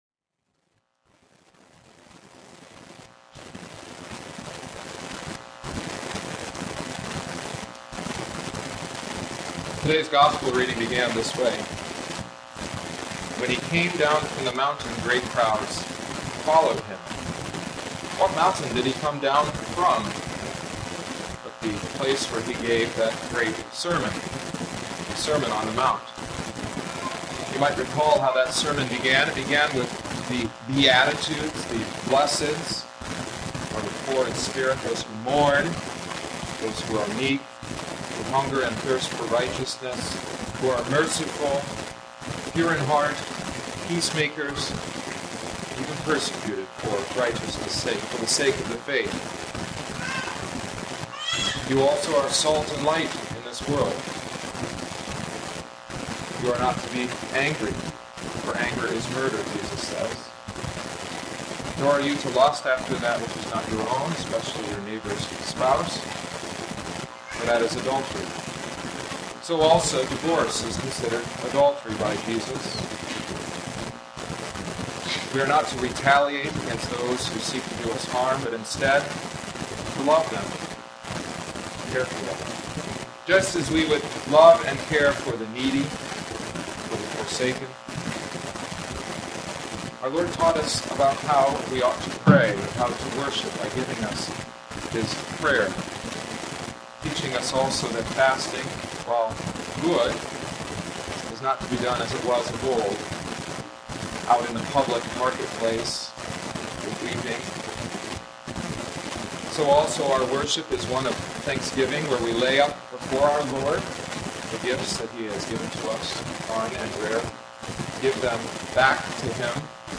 *Apologies for the atrocious sound quality. We had a power outage on Saturday (no audio) and Sunday’s connection was faulty. If you can suffer through the noise, there’s a sermon underneath.*